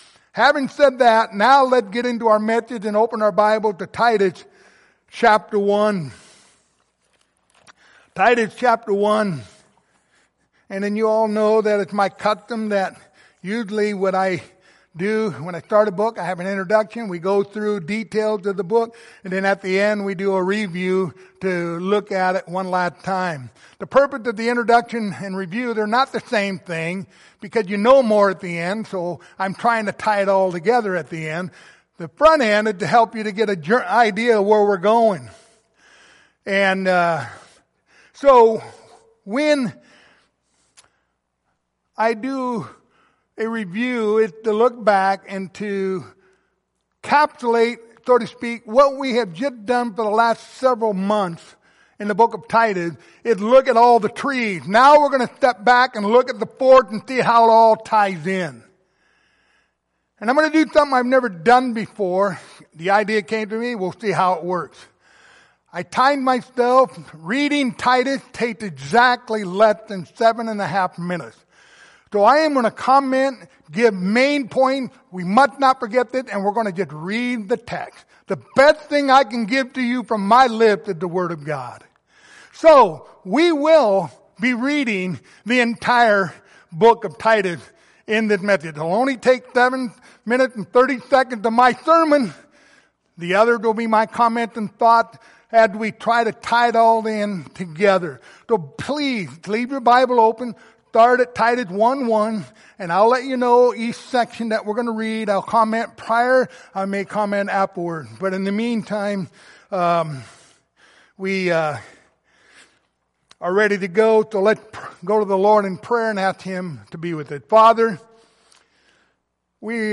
Pastoral Epistles Passage: Titus 1-3 Service Type: Sunday Morning Topics